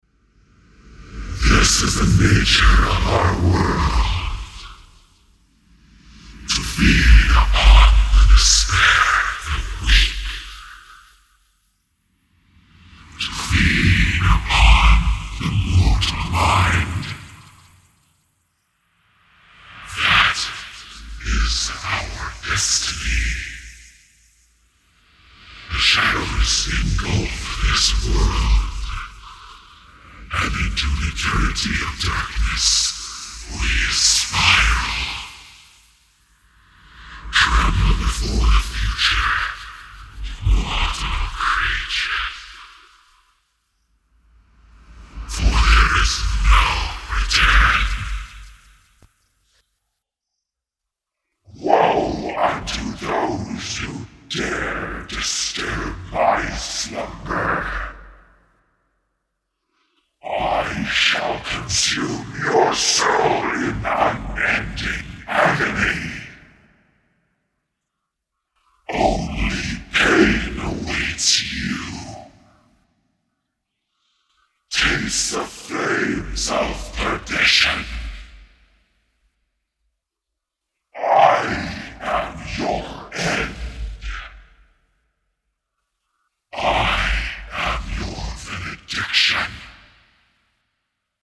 Some more refined voices.